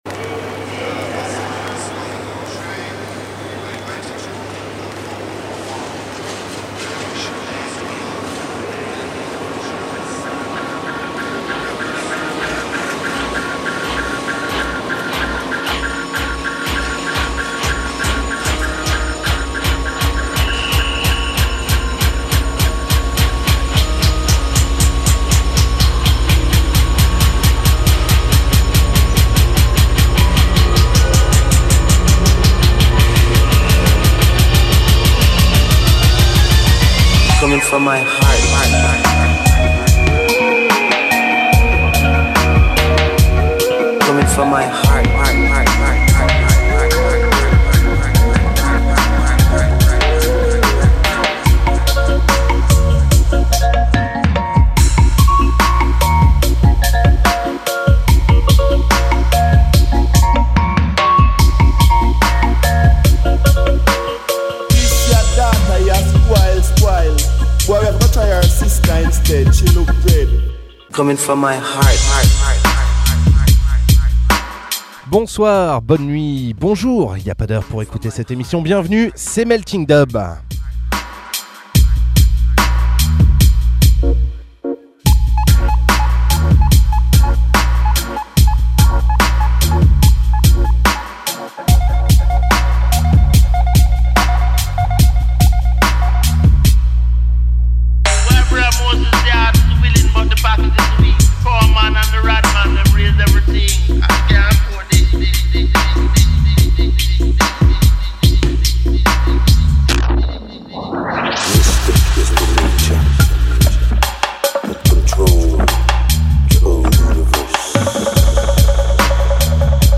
bass music , dub , musique , musique electronique , reggae